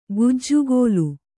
♪ gujjugōlu